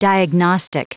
Prononciation du mot diagnostic en anglais (fichier audio)
Prononciation du mot : diagnostic